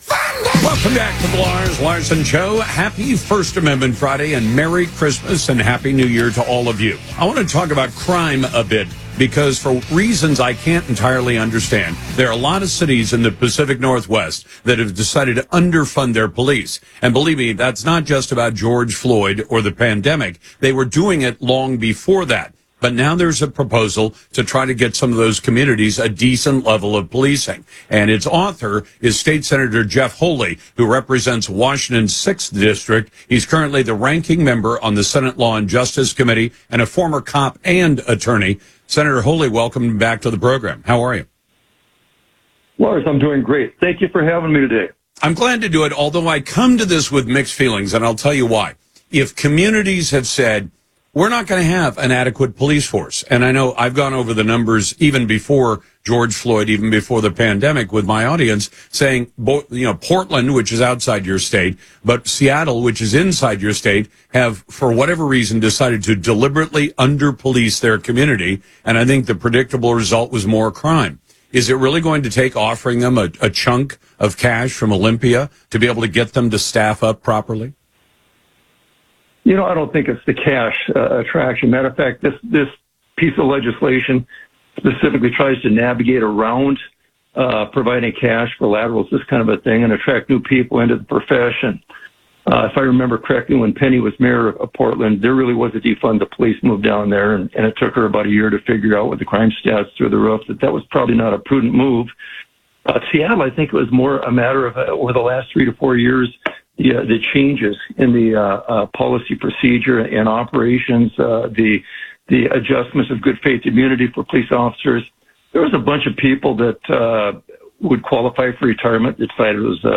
Senator Jeff Holy talks with Lars Larson about his bipartisan bill that would use $100 million to help hire more law-enforcement officers.